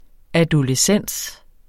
Udtale [ adoləˈsεnˀs ]